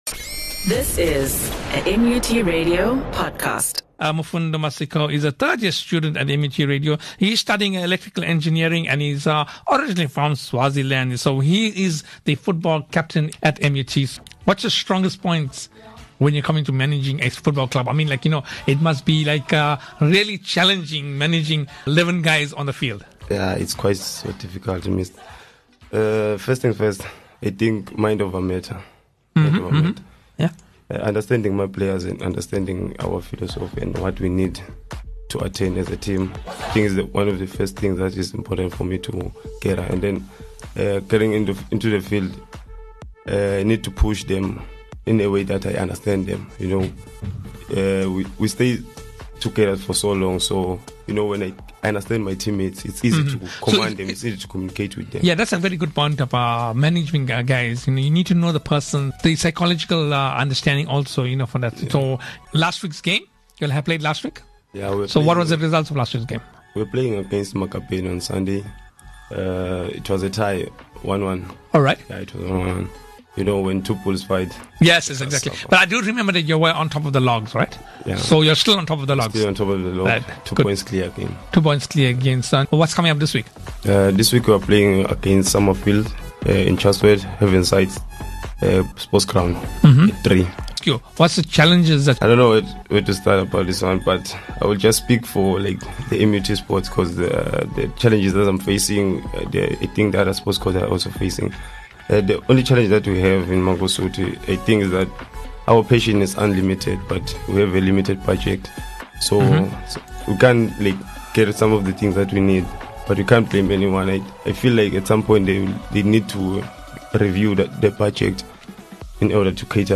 In this interview, he shares his leadership skills. He also expresses some of the challenges the football undergo.